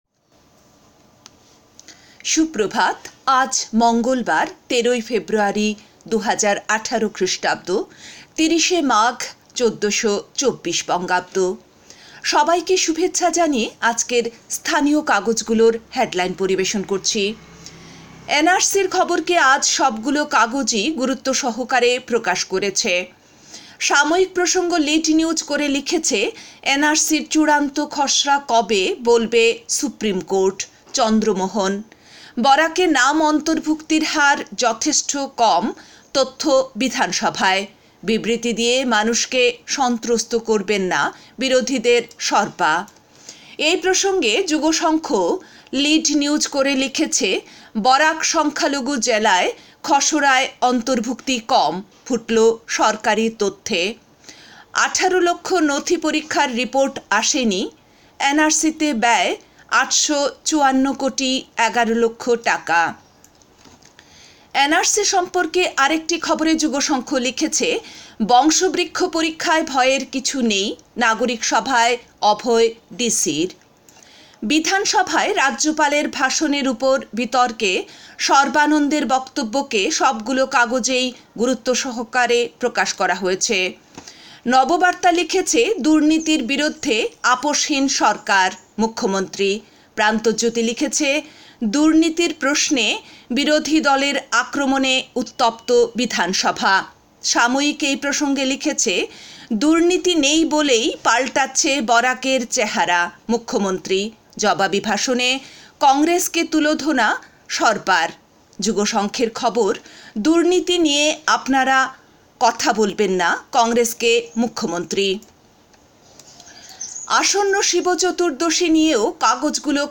A bulletin with all top headlines across categories.